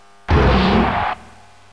Captain Falcon Crowd Cheer in Dairantou Smash Brothers.
Jigglypuff_Cheer_Japanese_SSB.ogg